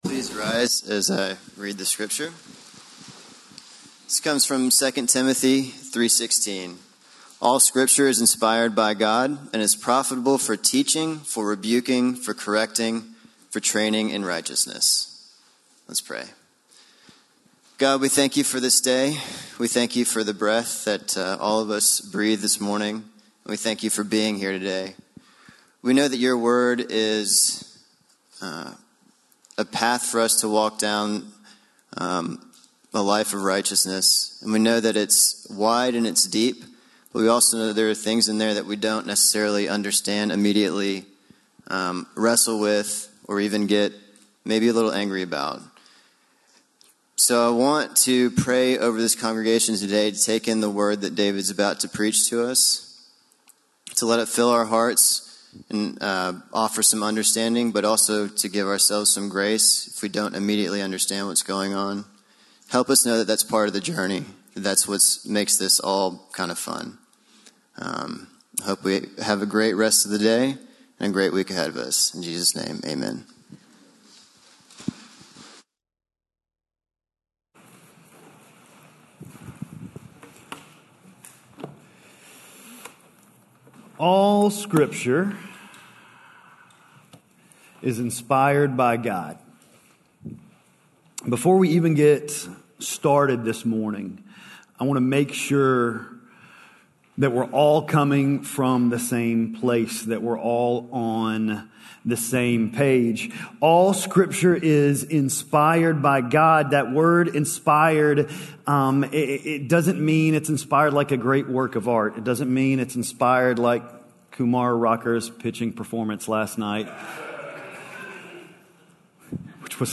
Scripture 2 Timothy 3:16 Sermon Audio https